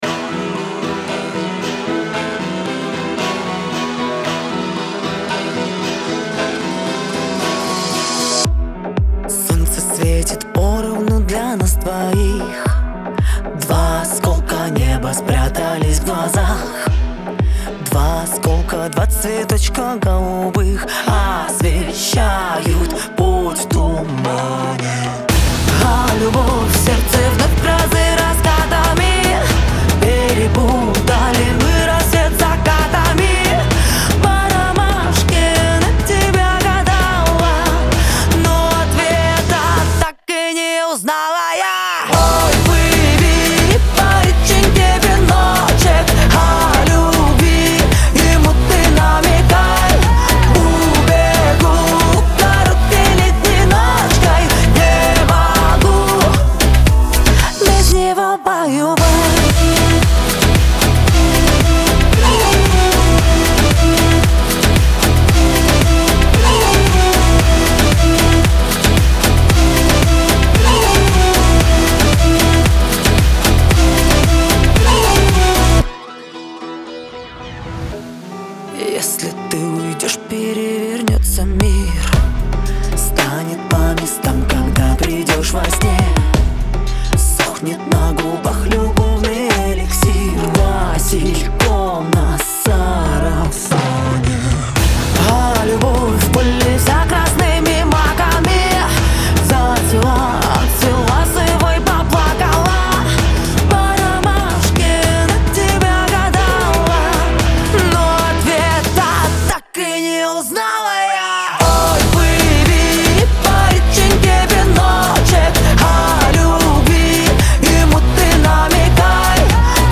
эстрада
весёлая музыка
pop